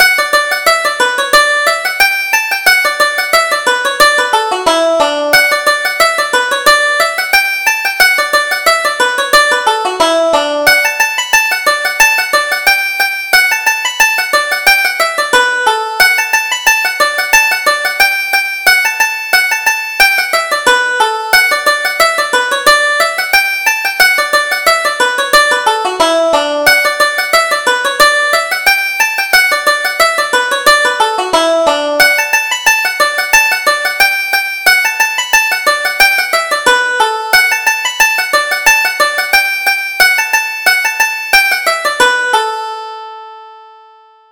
Reel: The Strawberry Blossom - 1st Setting